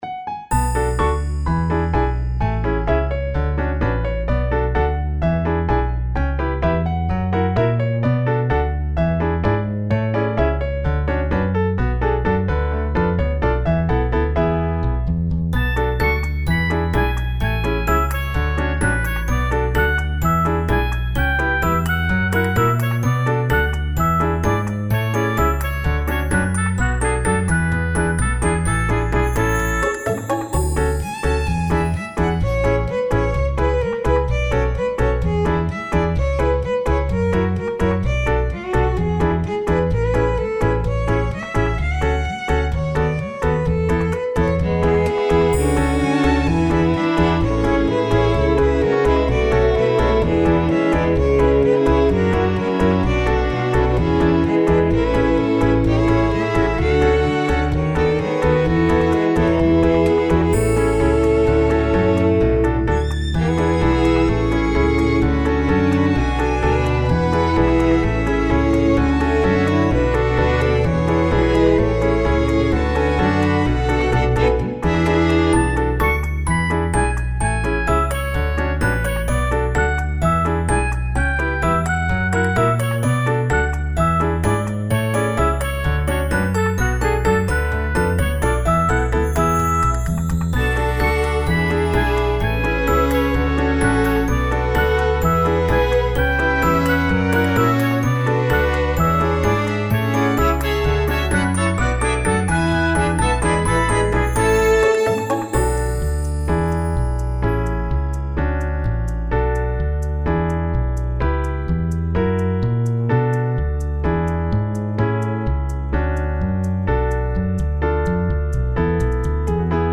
ファンタジー系フリーBGM｜ゲーム・動画・TRPGなどに！
おしゃれな町並みとかそういう感じのやつ。